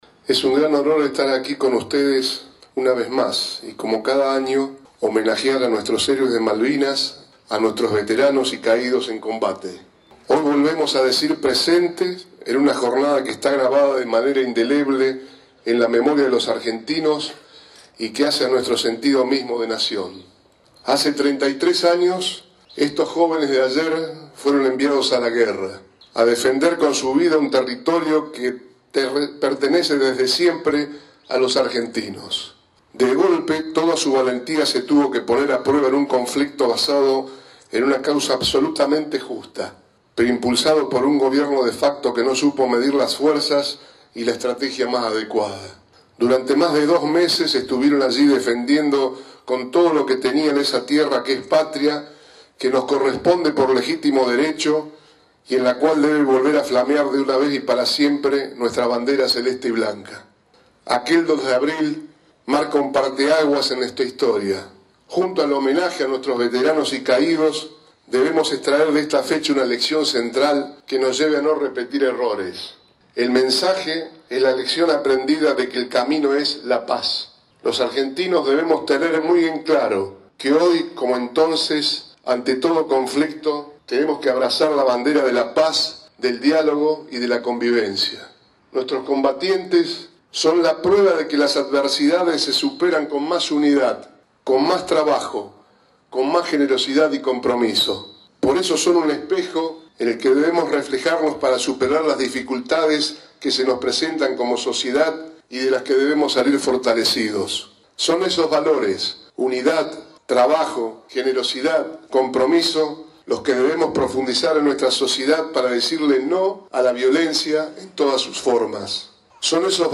El gobernador Bonfatti encabezó en la ciudad de Reconquista el acto oficial en homenaje a los Veteranos y Caídos en la guerra de 1982.
La ceremonia se llevó a cabo sobre la calle, frente al monumento en honor a los Caídos y Veteranos que se levantó en la plaza, donde se inscriben los nombres de los combatientes del departamento General Obligado que cayeron en el conflicto bélico.